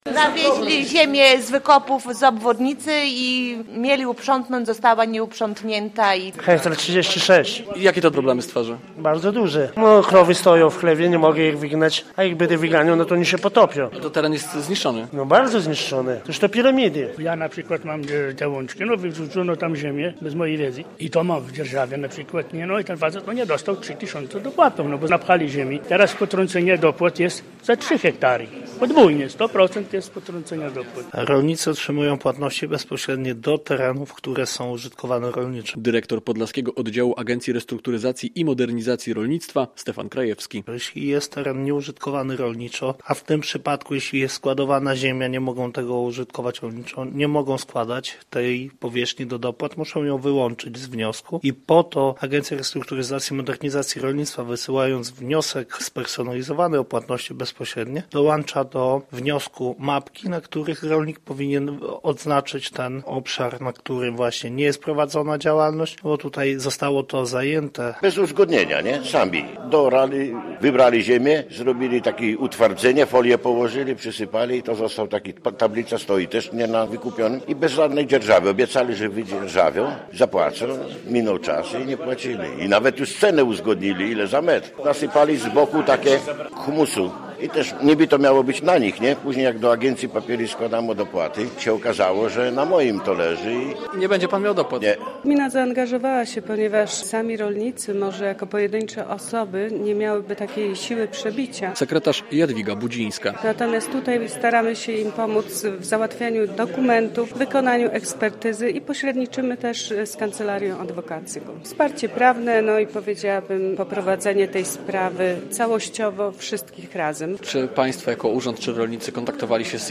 Rolnicy domagają się odszkodowań - relacja